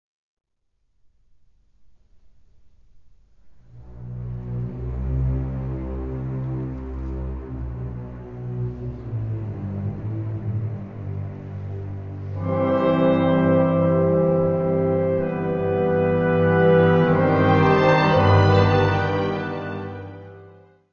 Área:  Música Clássica
Largo - Allegro moderato.